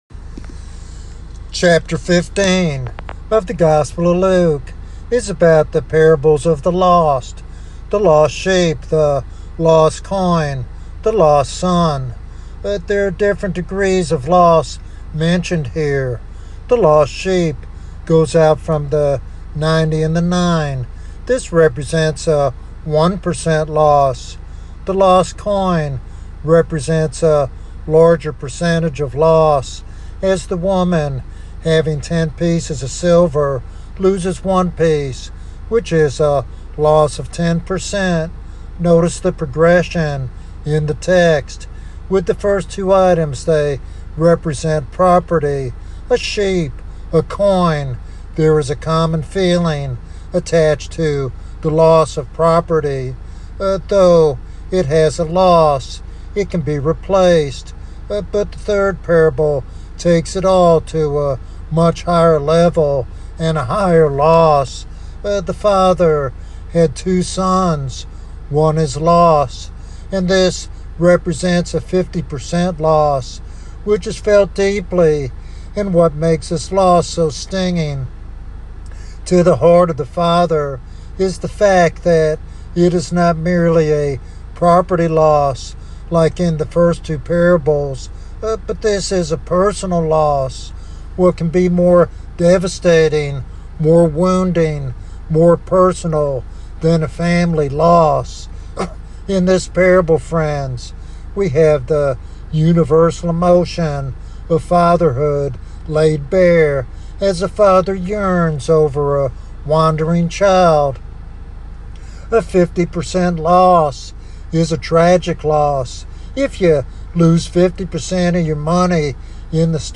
In this heartfelt sermon